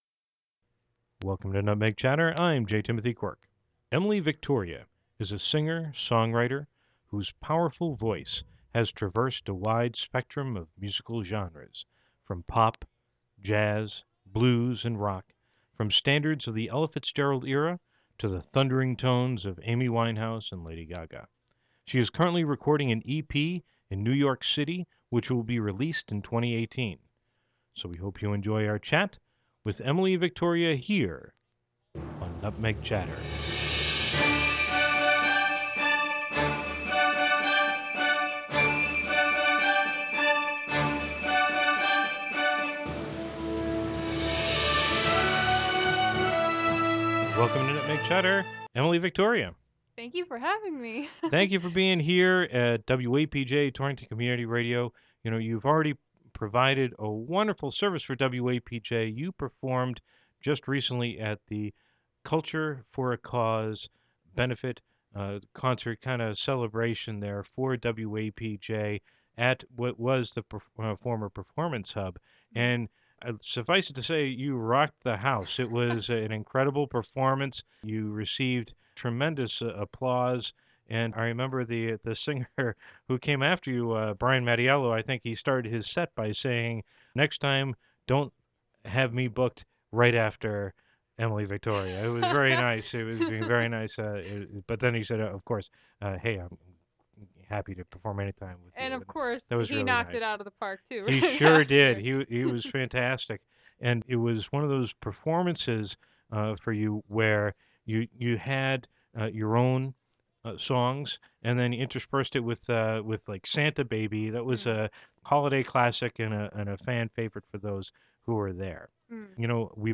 Categories: Radio Show, Torrington Stories